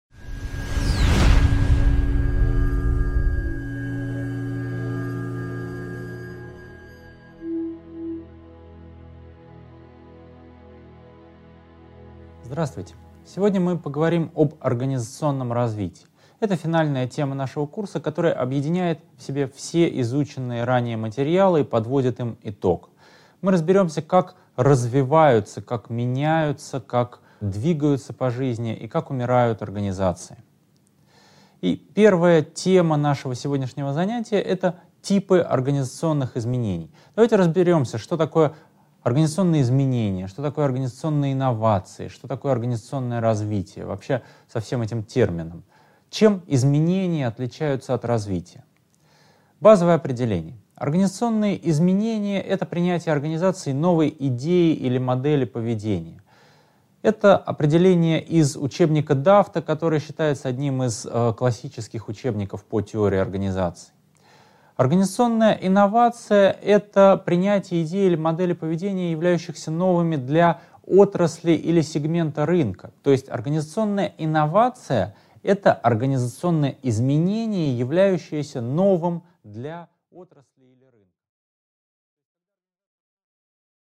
Аудиокнига 9.1. Типы организационных изменений | Библиотека аудиокниг